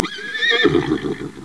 snd_21028_Horse.wav